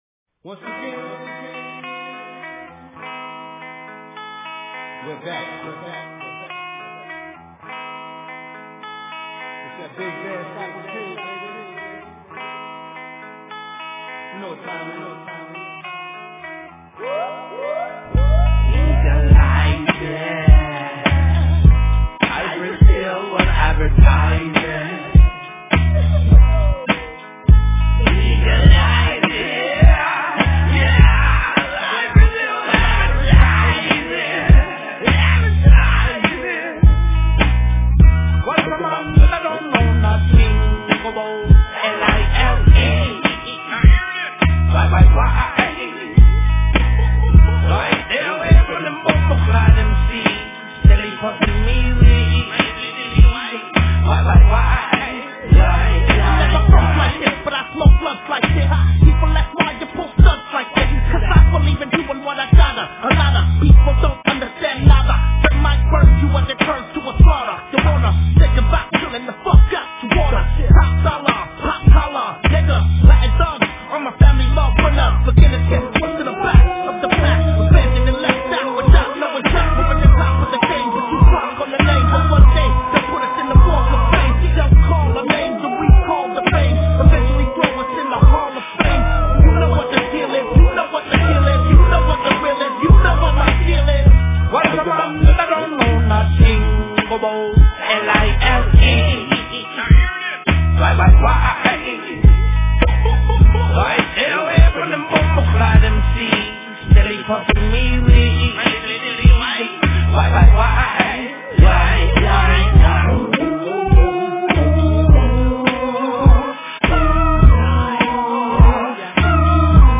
Назад в (rap)...
музыка